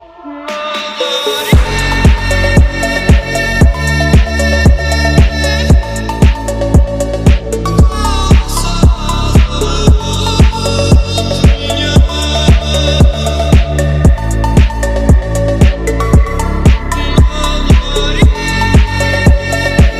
Phonk ремикс с уклоном